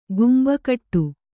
♪ gumba kaṭṭu